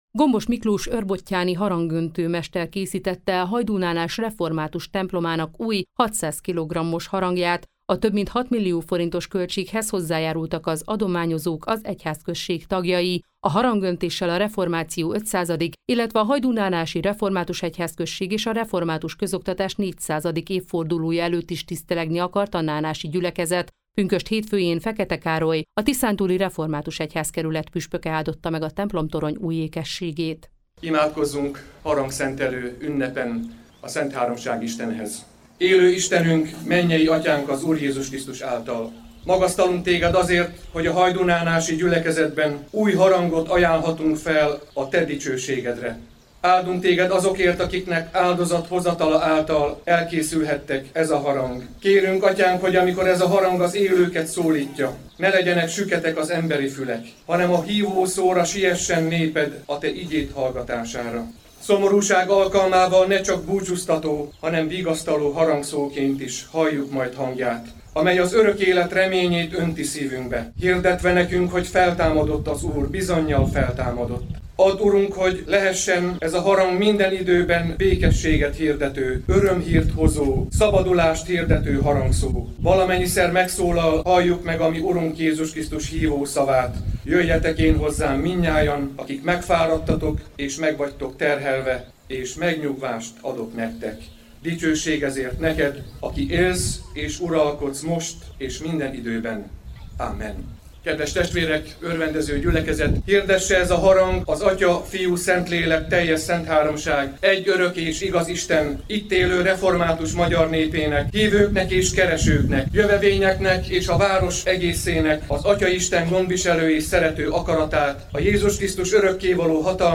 hnanas-harangszenteles.mp3